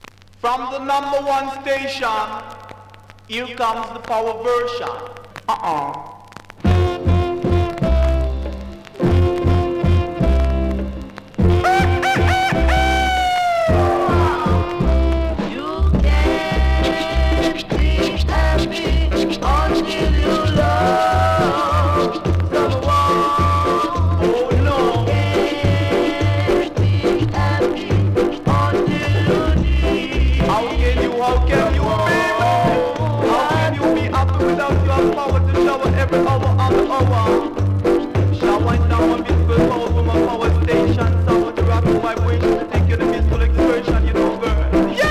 REGGAE 70'S